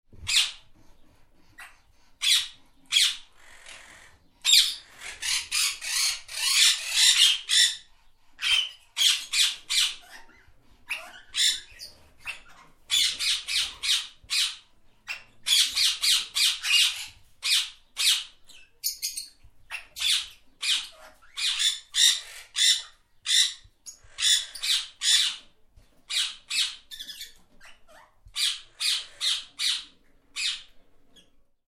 Exotic Birds
Animal Sounds / Bird Sounds / Sound Effects
Birds-sounds-parrots.mp3